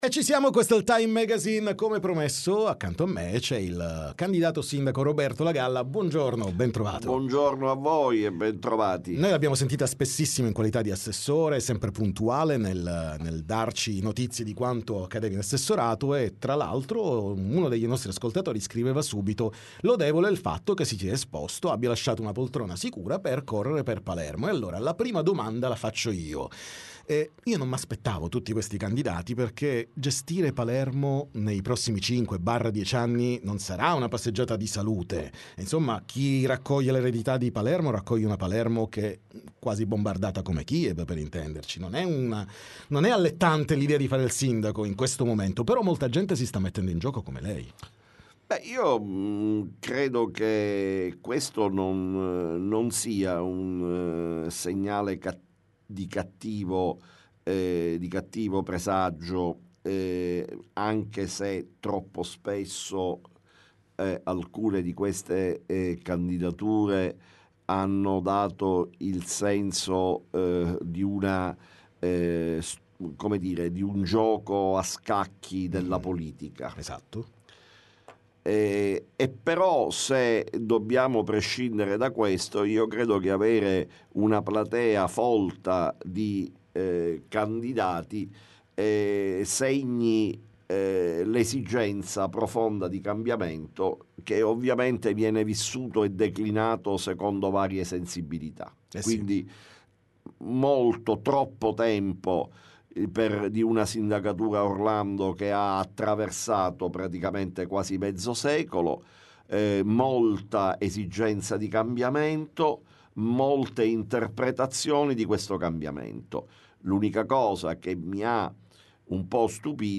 TM Intervista Roberto Lagalla